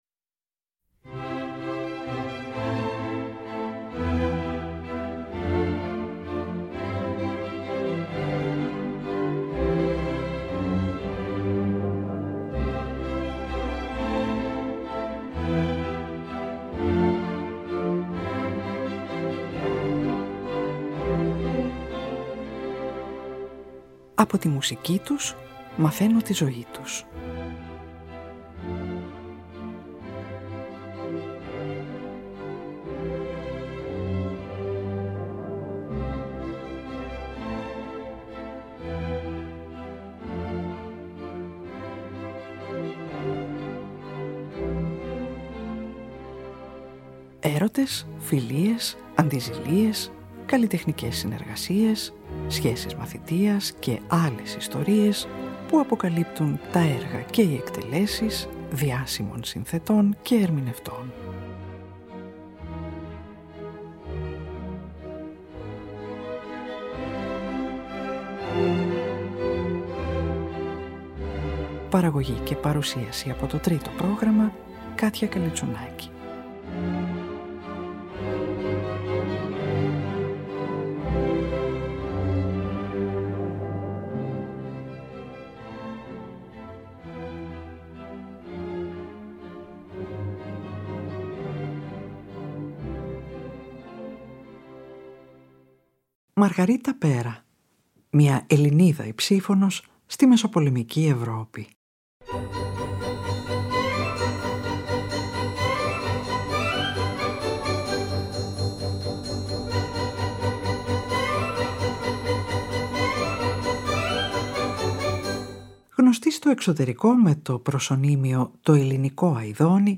ακούγεται σε άριες
στο πιάνο